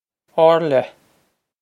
Or-leh
This is an approximate phonetic pronunciation of the phrase.